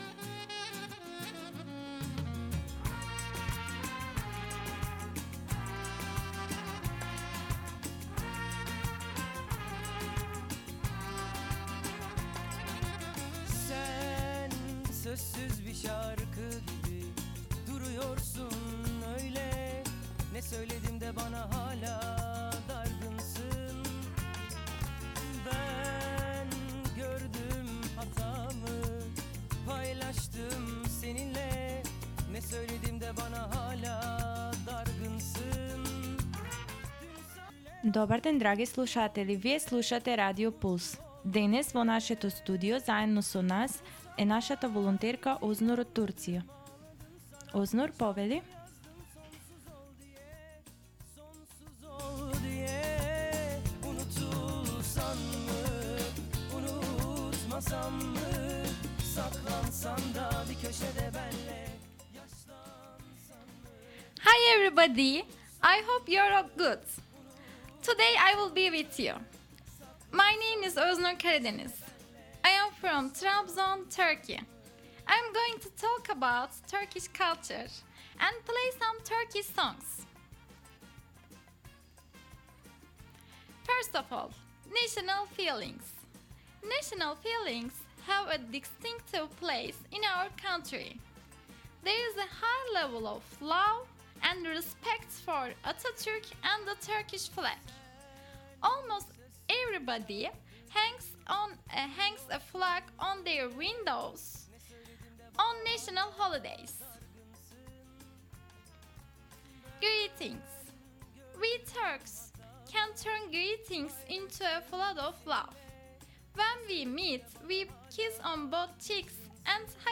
I am going to talk about Turkish culture., and play some Turkish songs.